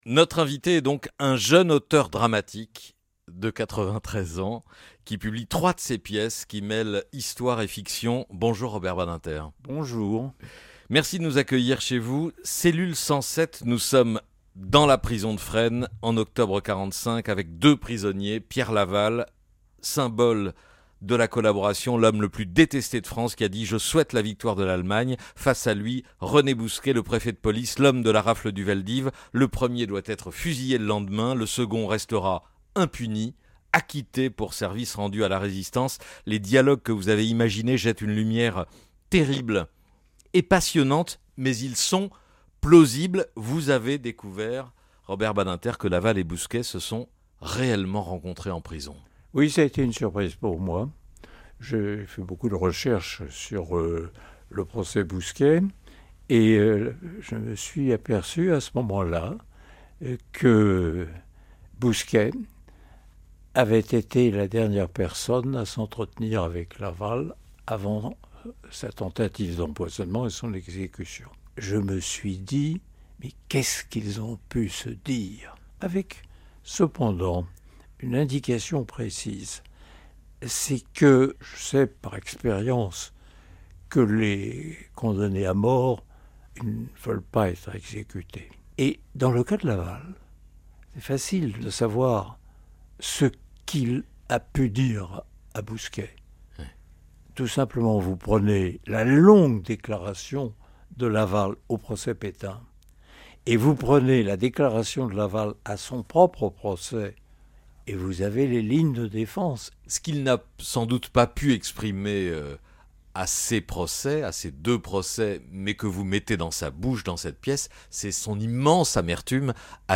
L’ancien garde des Sceaux Robert Badinter, se confie au micro de Patrick Cohen dans un grand entretien à l’occasion de la publication chez Fayard d’un recueil de trois pièces, Théâtre I, qui mêle justice, fiction et histoire. L’invité de Patrick Cohen raconte la naissance de sa passion pour l’art dramatique dans le Paris des années 1950, sous le charme de Sartre, Beauvoir et Ionesco.
Une interview diffusée en deux temps et à retrouver en intégralité ici.